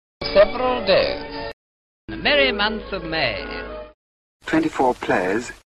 Here are authentic [eɪ] antiques from old films: